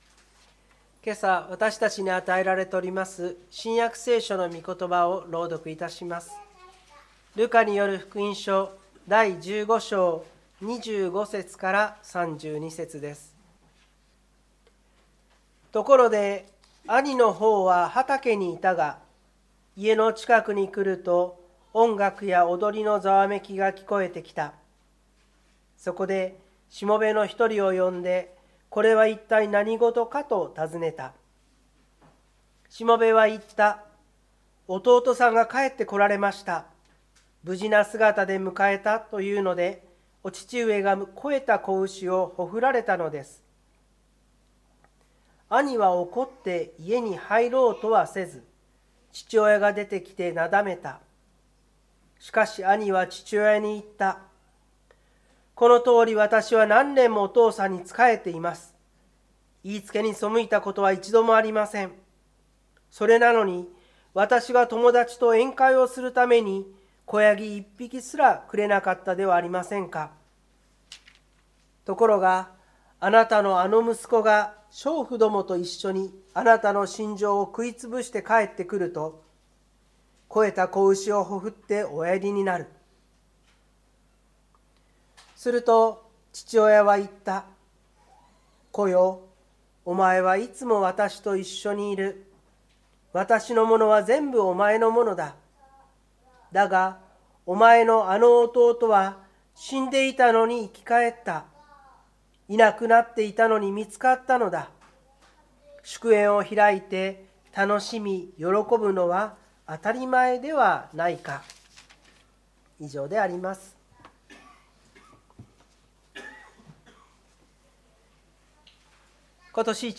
2024年12月29日朝の礼拝「憐みを示す神」千葉県我孫子市のキリスト教会
湖北台教会の礼拝説教アーカイブ。